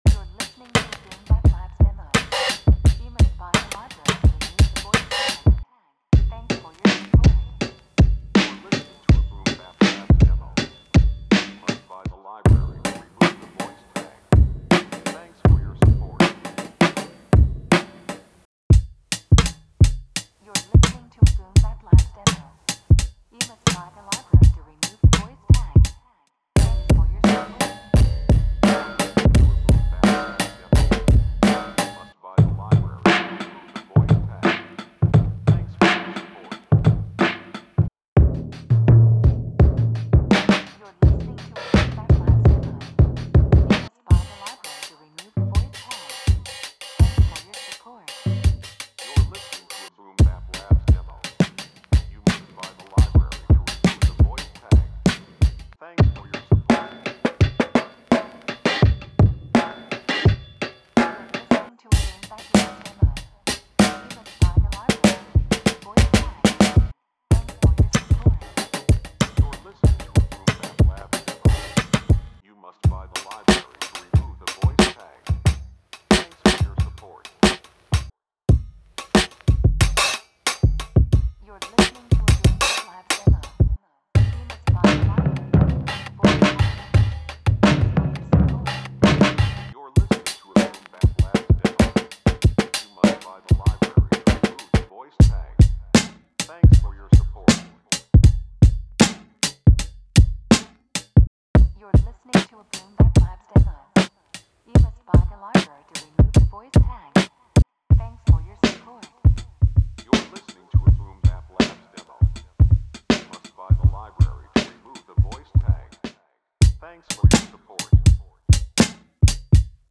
Here’s a collection of hard hitting original drum breaks. No clean sounds here. All the drums have been processed with special treatment, including tape saturation, spring reverb, wow and flutter, room noise, 12 bits and more. You’ll get an eclectic collection of loops from 70s funk feels to more offbeat rhythms from 60 to 97 BPM (Most of them around 84) All files are in WAV format with a sampling rate of 44.1 kHz and a 16-bit resolution.